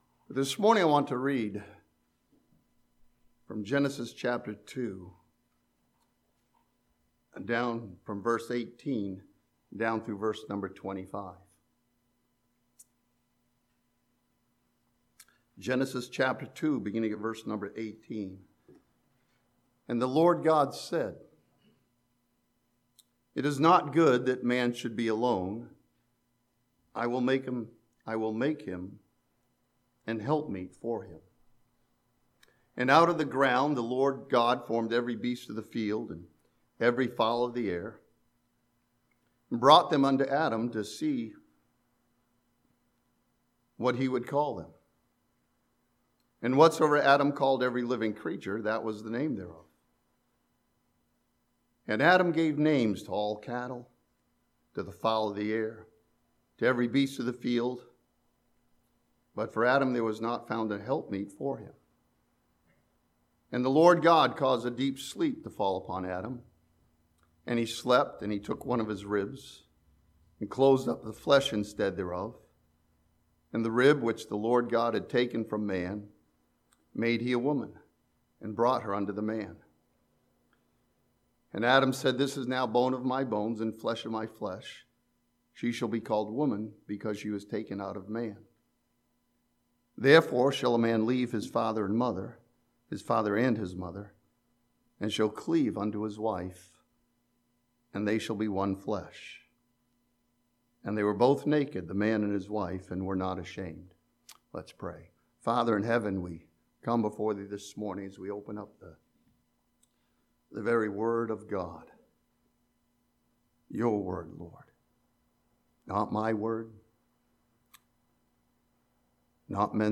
This sermon from Genesis chapter 2 looks at God's design for marriage as proclaimed in the Creation account.